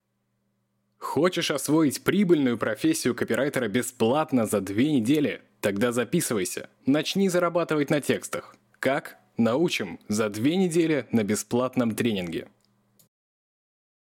AKG P120 Behringer U-Phoria Umc22